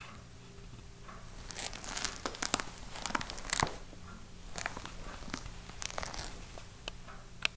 Since this is a podcast, I will let you hear what my knees sound like when I do a squat.
You can hear that my knees are still a mess, but I experienced absolutely no pain performing this squat. by exercising every day, the muscles around my knee joints have taken much of the stress off of the actual joint.
knees.mp3